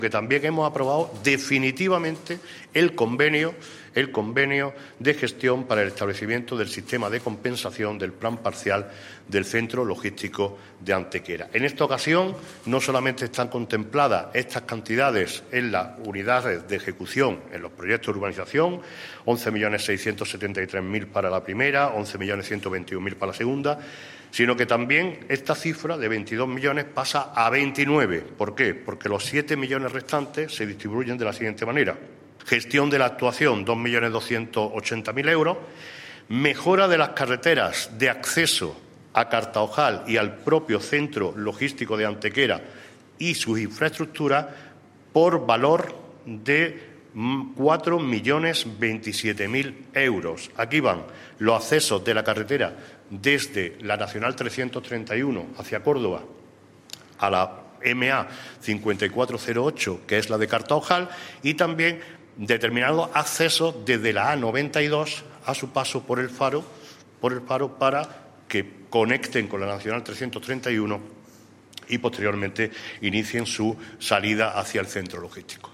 El alcalde de Antequera, Manolo Barón, acompañado por la teniente de alcalde de Urbanismo, Teresa Molina, y del concejal delegado de Obras e Inversiones, José Ramón Carmona, ha informado hoy en rueda de prensa de la aprobación (jurídica, administrativa y urbanísticamente) de un millón y medio de metros cuadrados de nuevo suelo industrial solucionando así tras más de una década el bloqueo del Centro Logístico de Antequera.
Cortes de voz